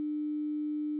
3. Oberwelle 300Hz
APRecht300Hz.au